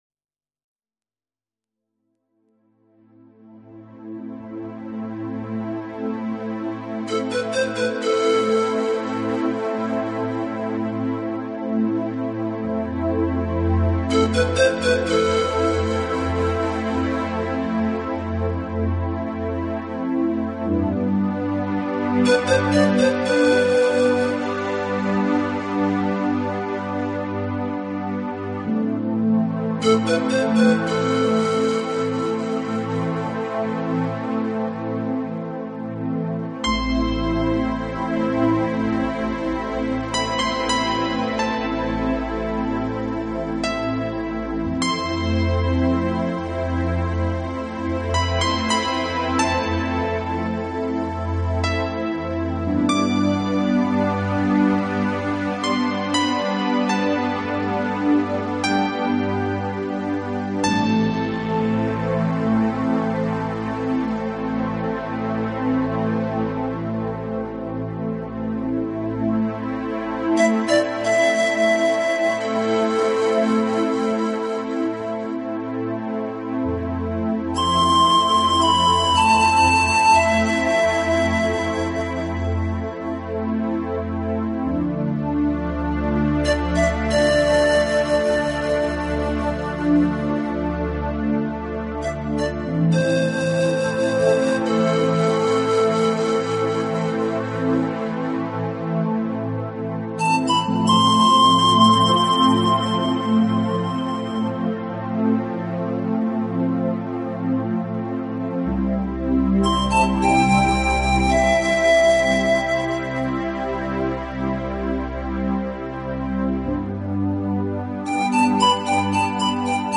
流水、雀鸟之声，能镇静人的情绪，松弛我们的身心，而且给人一种返回
从其不落俗套编曲，精简的乐器配置，使每首曲子都呈现出清新的自然气息。